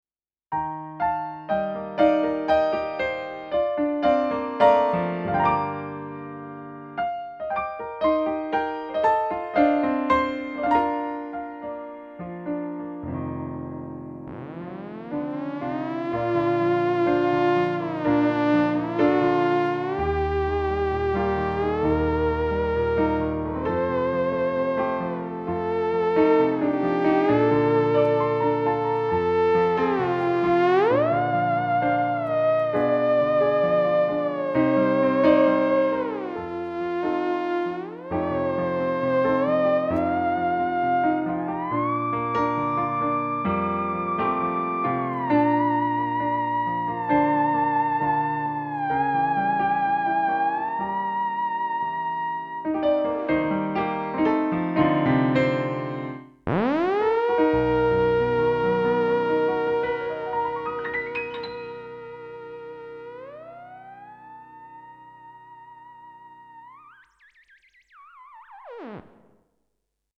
Ska dock försöka isolera potten, verkar ha kommit damm eller något i för det brusar lite i ett visst läge :?
Thereminen har fått ett litet reverb samt en sparsam EQ, i övrigt är det helt oredigerat.
Du låter lite darrig på handen, annars finfint :tumupp:
Det ska darra när man spelar theremin... :D